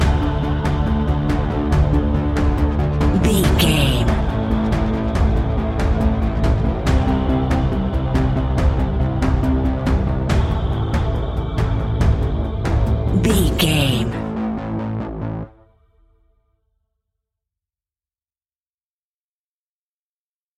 In-crescendo
Thriller
Aeolian/Minor
ominous
eerie
horror music
Horror Pads
horror piano
Horror Synths